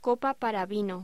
Locución: Copa para vino
voz